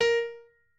pianoadrib1_20.ogg